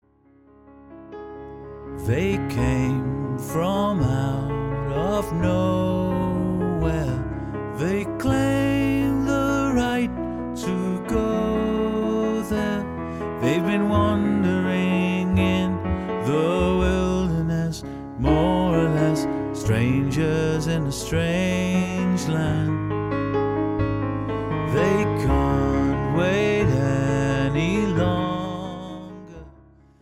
collection of family stories in song